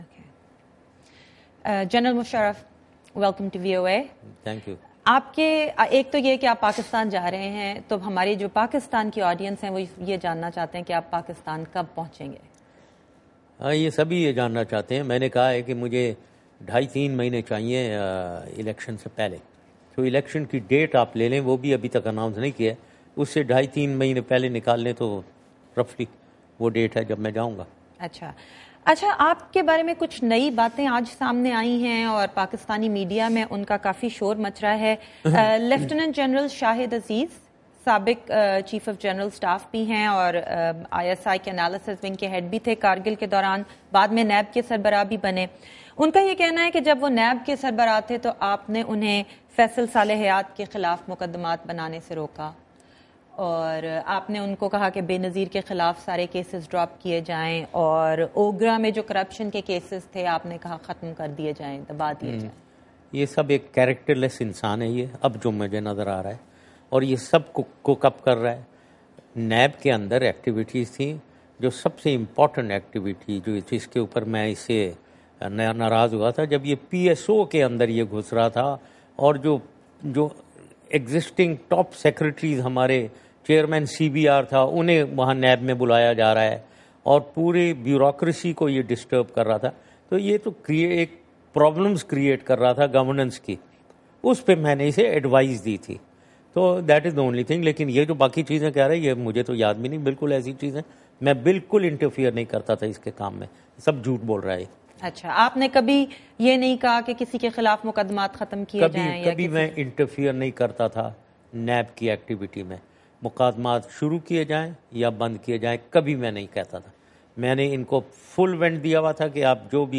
پرویز مشرف کا وائس آف امریکہ کے ساتھ خصوصی انٹرویو